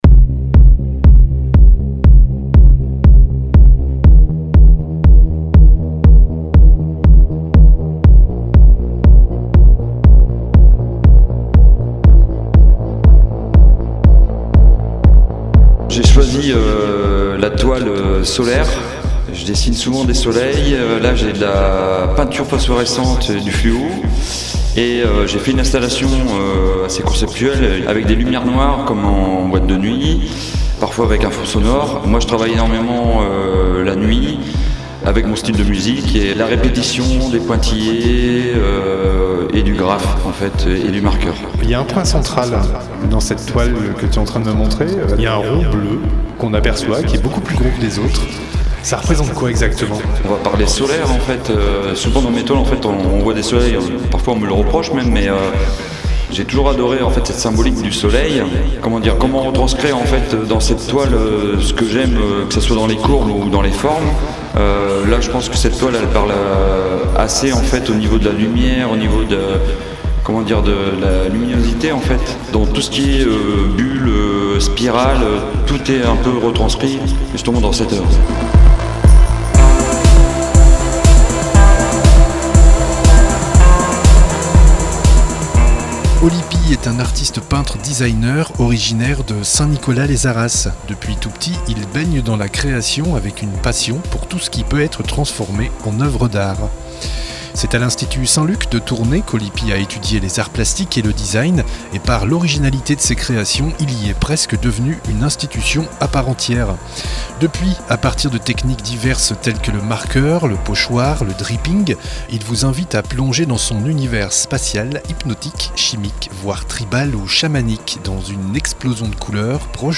REPORTAGES/ENTRETIENS